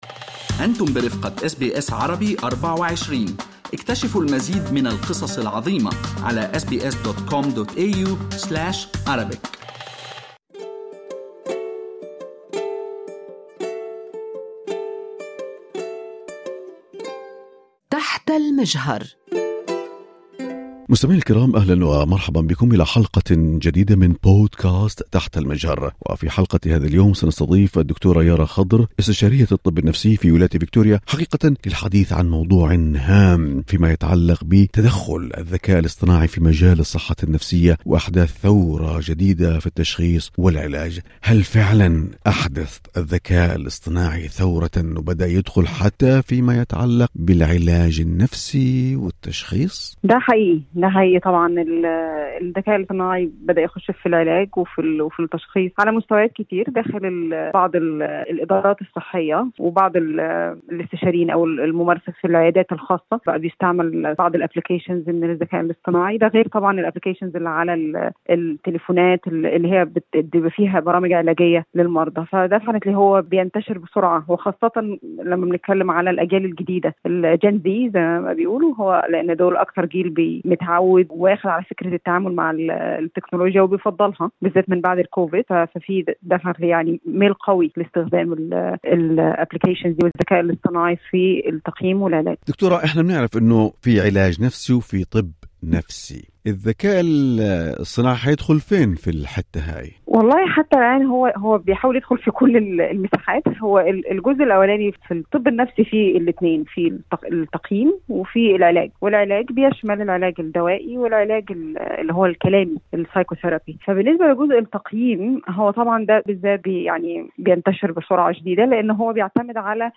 الذكاء الاصطناعي يحدث ثورة في علاج الصحة النفسية: استشارية نفسية تشرح مخاطره واستخداماته